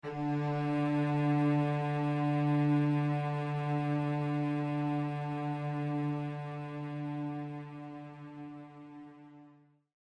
Нота: Ре первой октавы (D4) – 293.66 Гц
Note3_D4.mp3